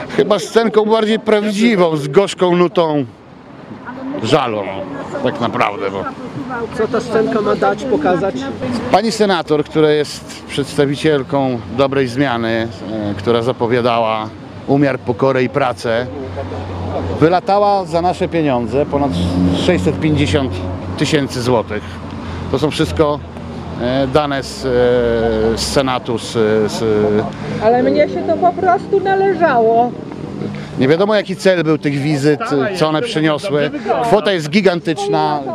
Później scenkę powtórzyli na Placu Marii Konopnickiej w centrum Suwałk.